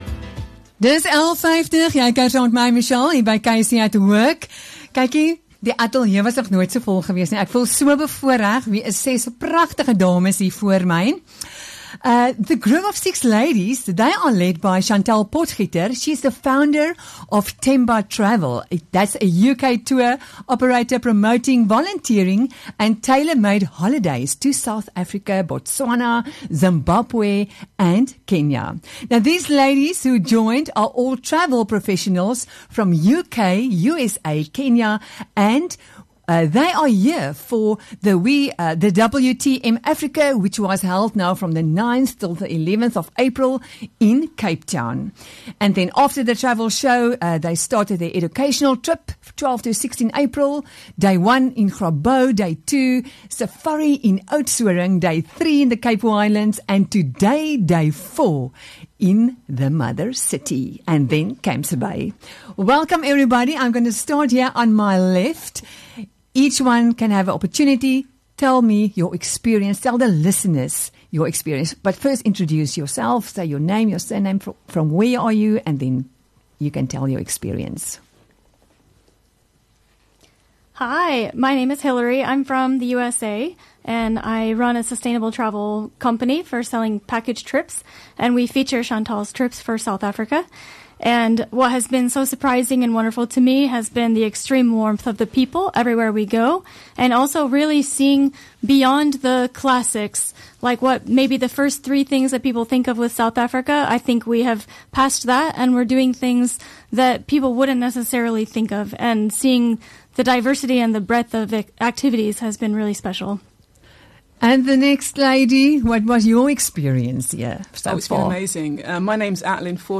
6 ladies sharing their experiences